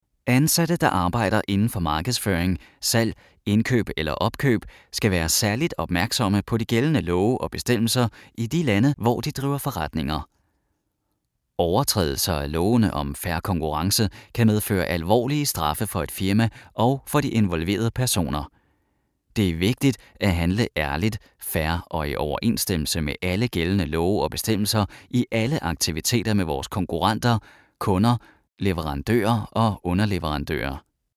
I specialize in the natural delivery, having a friendly and likeable voice the audience will feel comfortable with.
Sprechprobe: eLearning (Muttersprache):